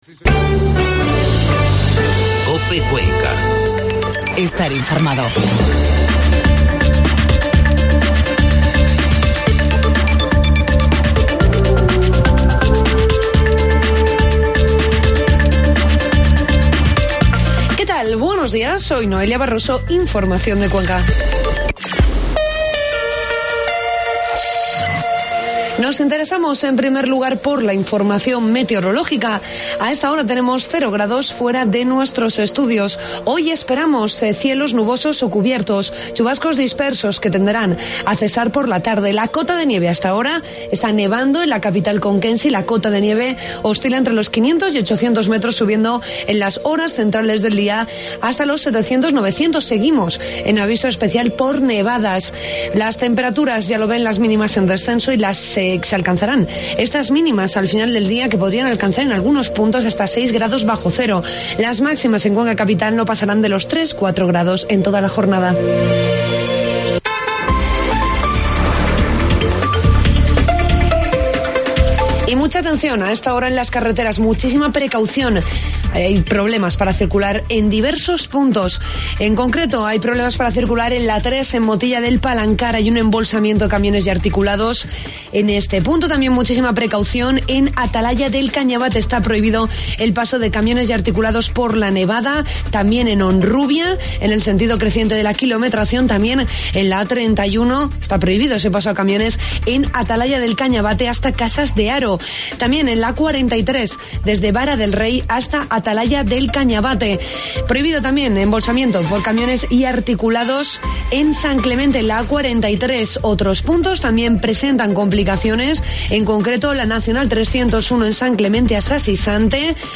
Informativo matinal 5 de febrero